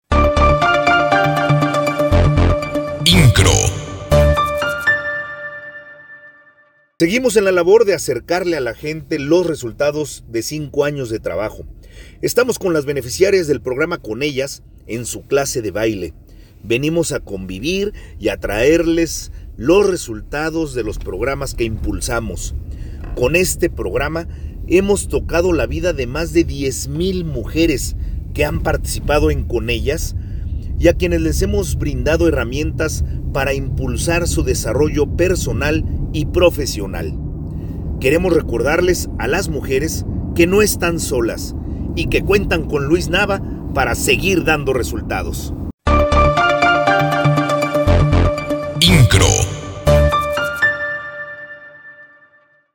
El Presidente Municipal de Querétaro, Luis Nava, en compañía de su esposa y Presidenta del Patronato del Sistema Municipal DIF, Arahí Domínguez, acompañaron a mujeres del programa Con Ellas a una clase de baile que forma parte de sus múltiples actividades y talleres, y donde el Alcalde aprovechó para platicarles los resultados de 5 años al frente de la administración en el que resalta este programa que brinda herramientas a las mujeres para mejorar su calidad de vida y la de sus familias.